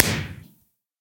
blast.ogg